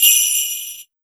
176UKPERC1-L.wav